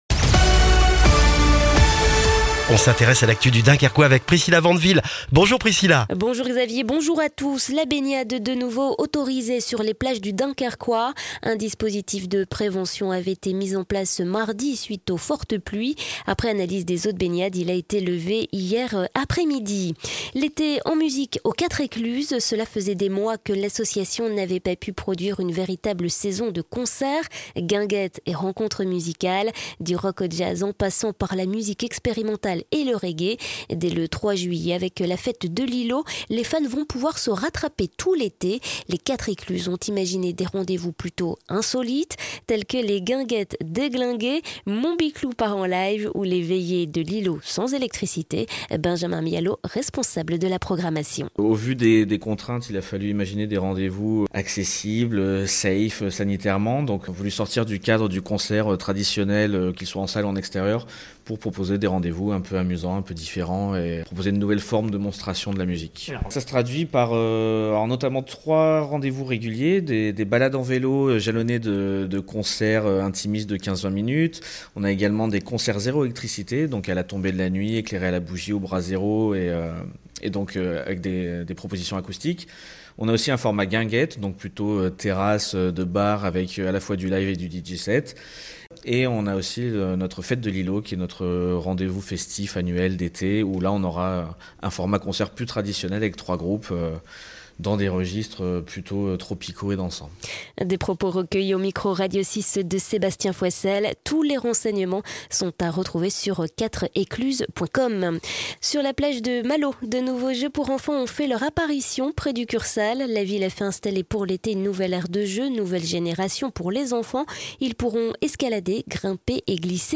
Le journal du jeudi 24 juin dans le Dunkerquois